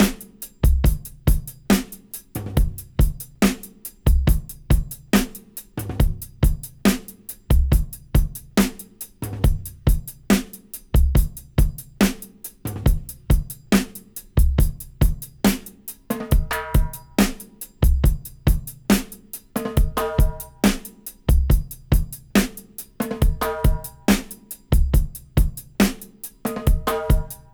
70-DRY-04.wav